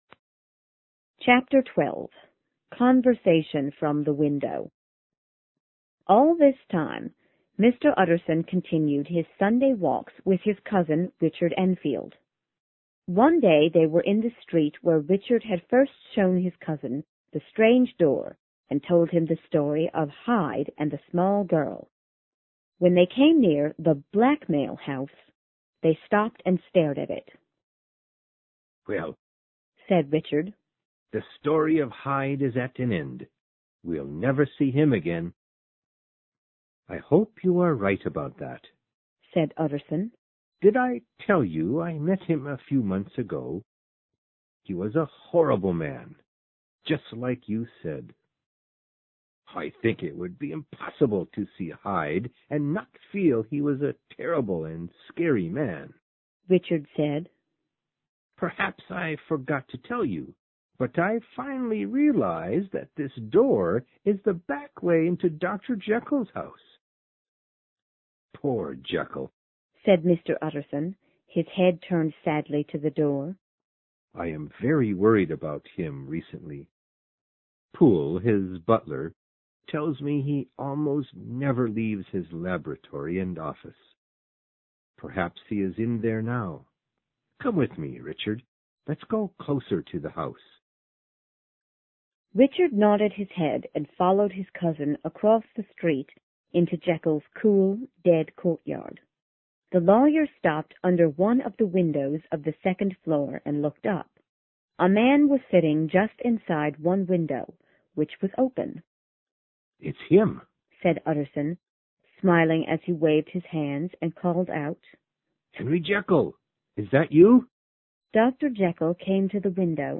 有声名著之化身博士12 听力文件下载—在线英语听力室